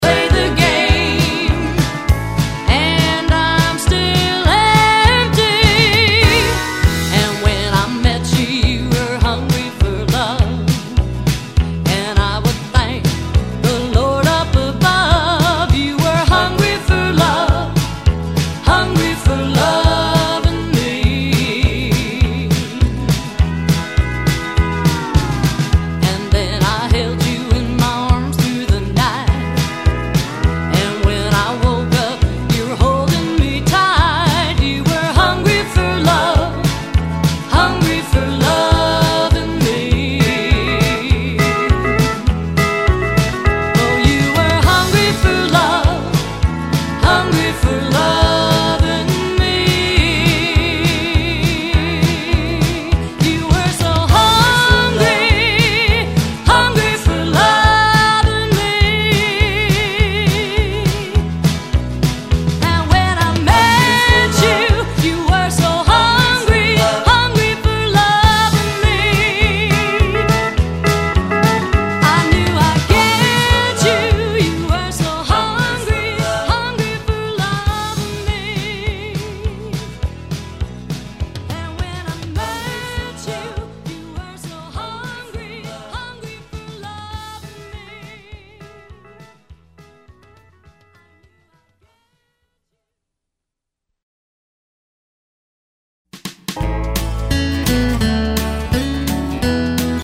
Pop European style dance tempo
A violin, cello, classic Pop verison